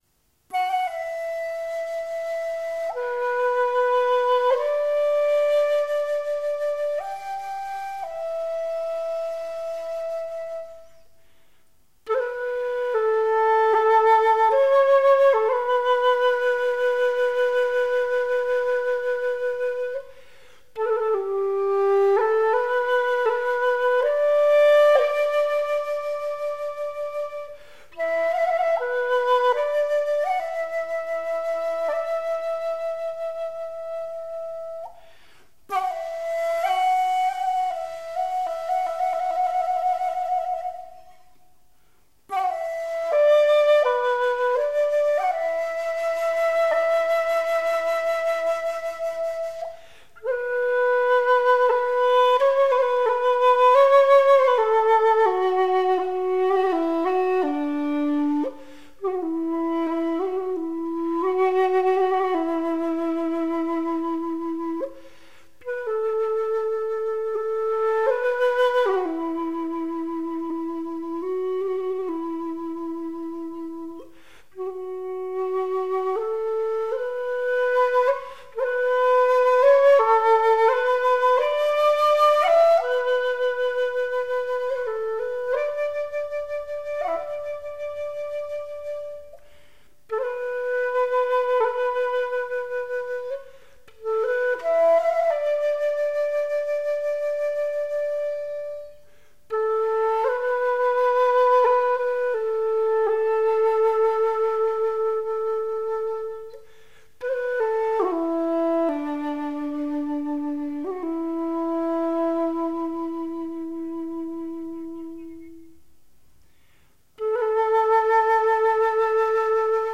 簫
他的音樂在優雅的古典情境與現代音樂美學間自由穿梭，有著名士派的瀟灑、水墨畫的詩意、東方音樂的幽雅，與西方音樂的大氣。